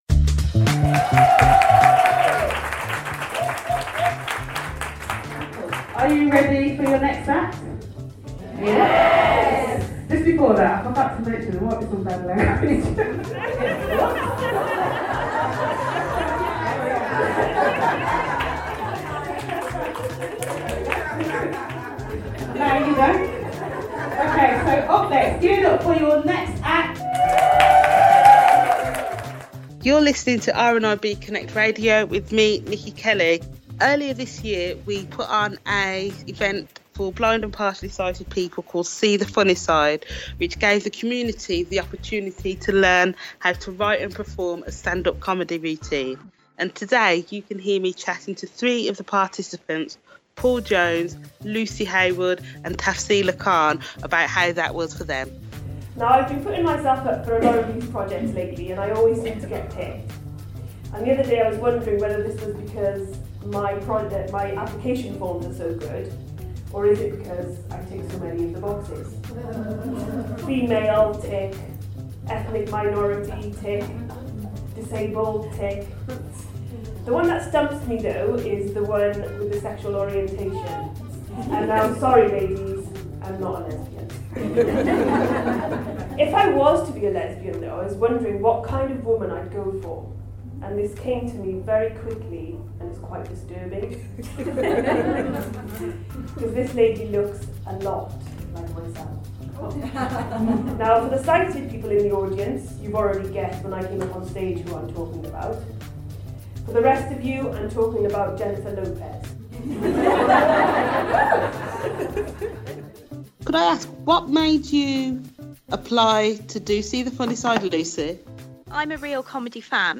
chats to three of the participants